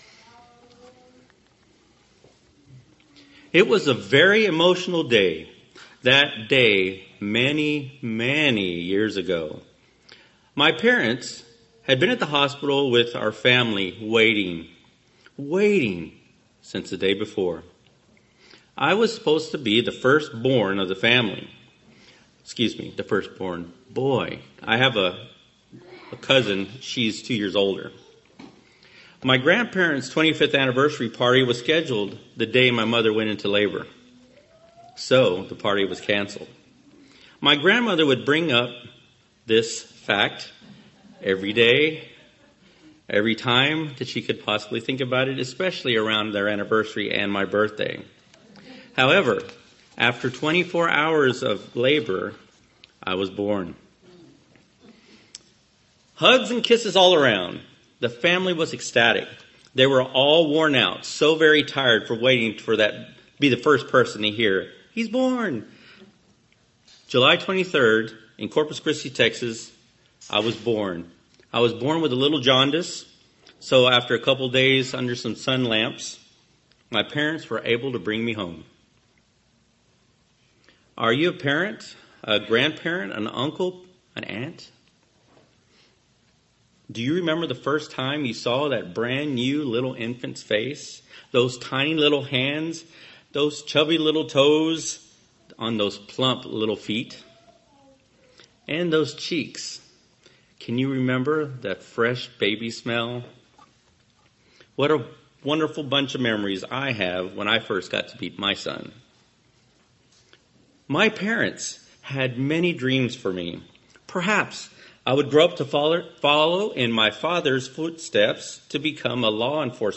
Sermons
Given in Lawton, OK